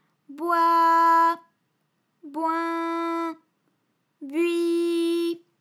ALYS-DB-001-FRA - First, previously private, UTAU French vocal library of ALYS
boi_boin_bui.wav